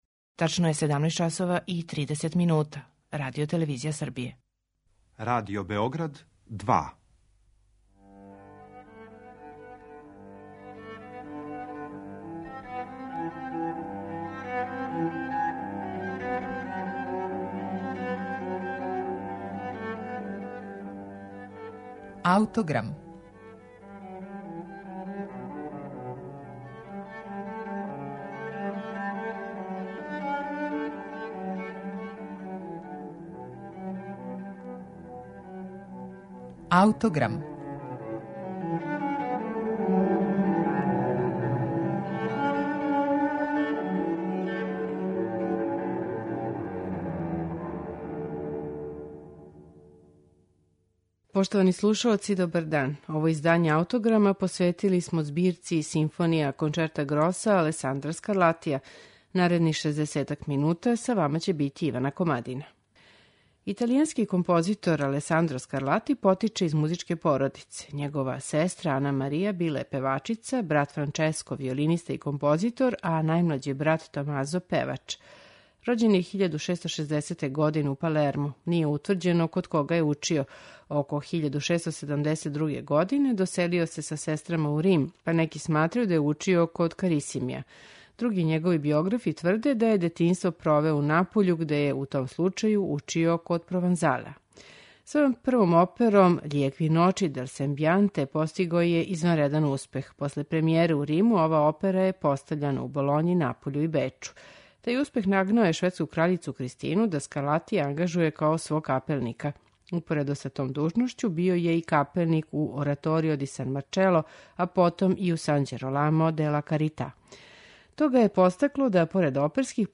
флауте
труба
обоа
камерни оркестар